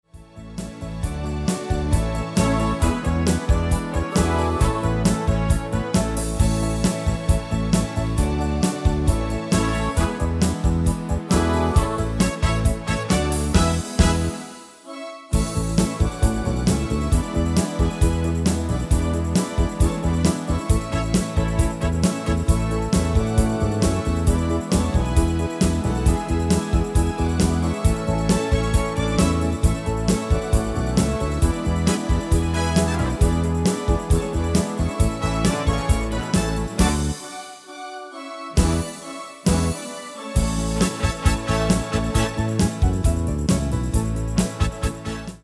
Demo/Koop midifile
Genre: Duitse Schlager
Toonsoort: A
- Vocal harmony tracks
Demo's zijn eigen opnames van onze digitale arrangementen.